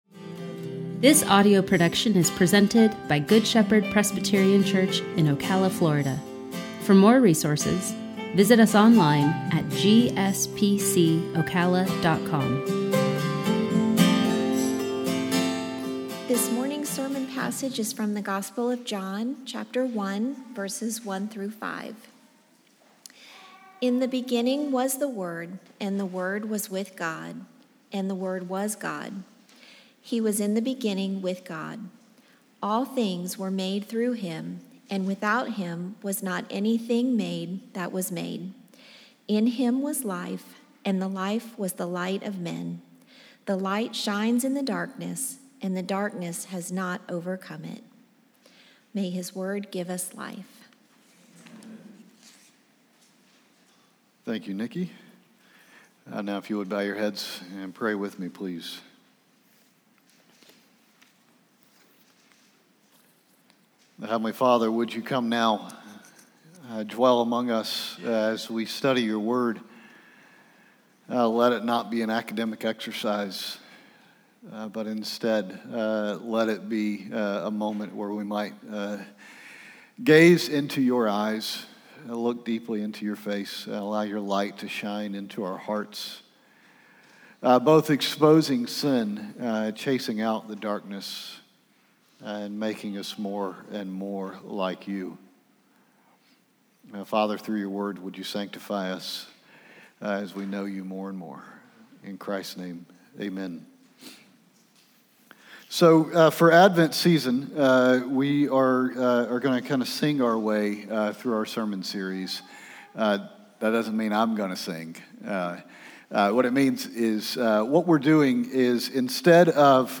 Sermon Audio 11/29/20 – Advent Series – “Light & Life” John 1:1-5 – Good Shepherd Presbyterian Church